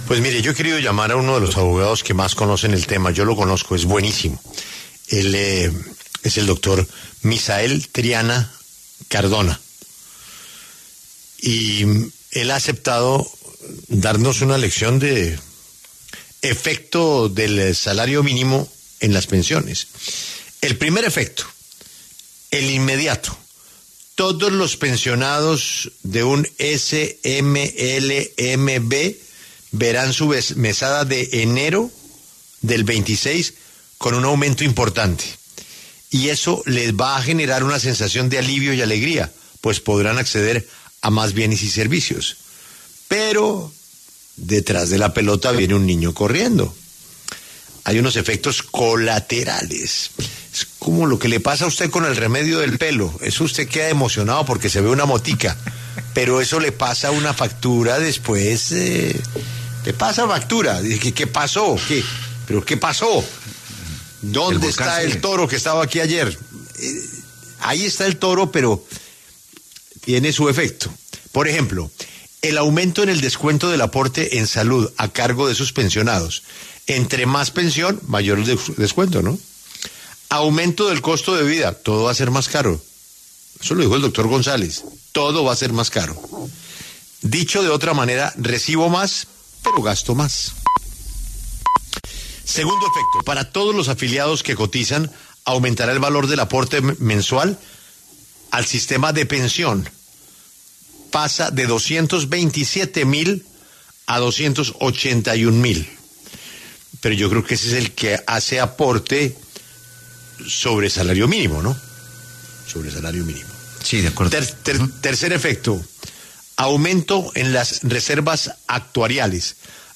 escuchó y respondió las preguntas de varios oyentes de La W